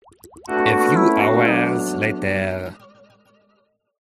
spongebob-transition-a-few-hours-later.mp3